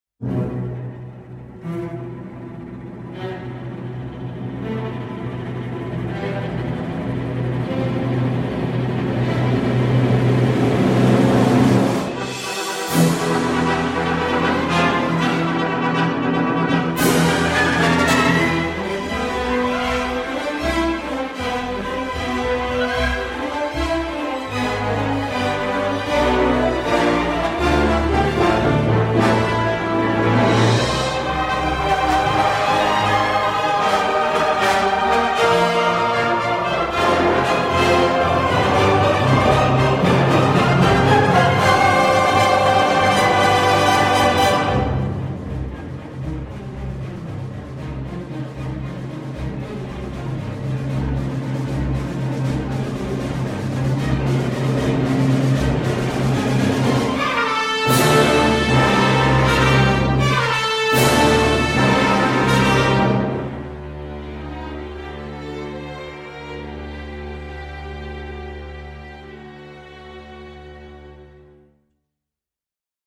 • GRAND ORCHESTRAL SCORE IN THE TRADITION OF JOHN WILLIAMS